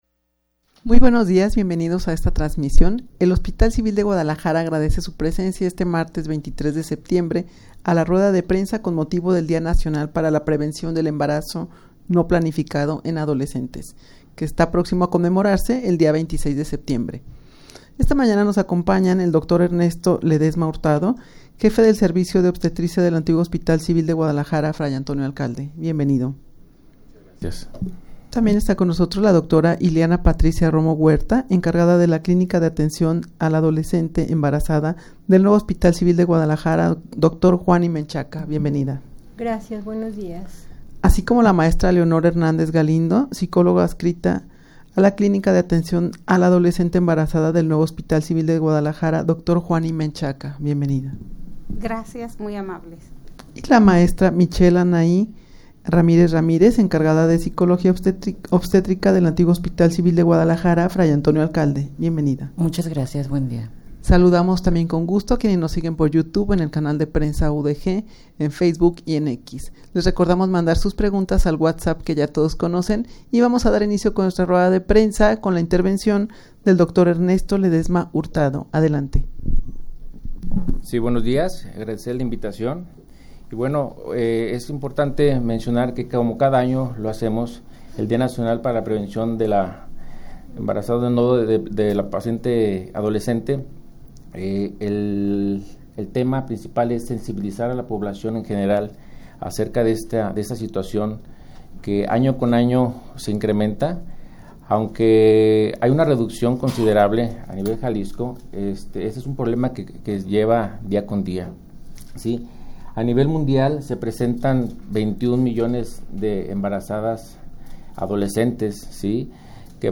Audio de la Rueda de Prensa
rueda-de-prensa-con-motivo-del-dia-nacional-para-la-prevencion-del-embarazo-no-planificado-en-adolescentes.mp3